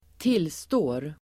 Ladda ner uttalet
Uttal: [²t'il:stå:r]